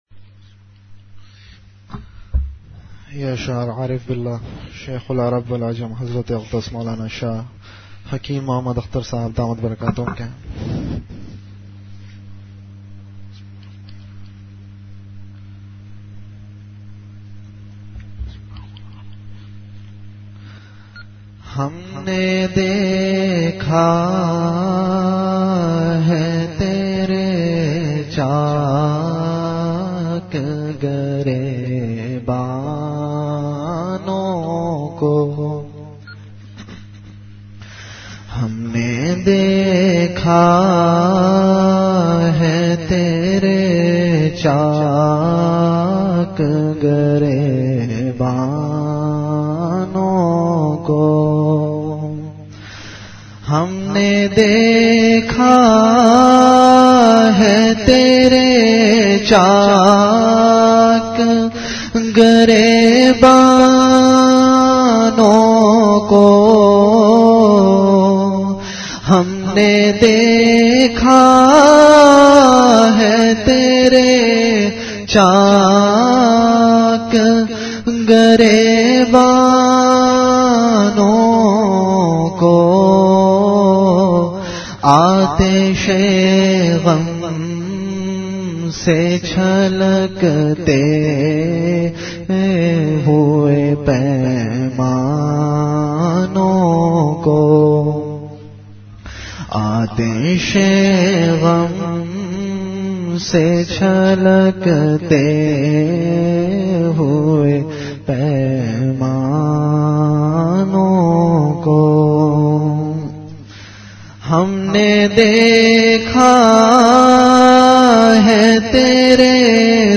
Delivered at Home.
Event / Time After Isha Prayer